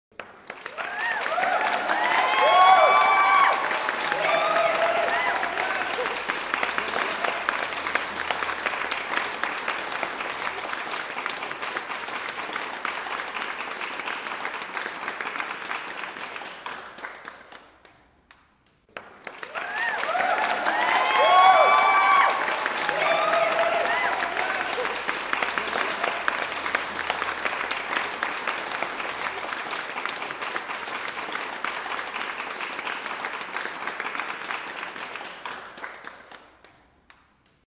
دانلود آهنگ دست و جیغ و هورا جمعیت از افکت صوتی انسان و موجودات زنده
جلوه های صوتی
دانلود صدای دست و جیغ و هورا جمعیت از ساعد نیوز با لینک مستقیم و کیفیت بالا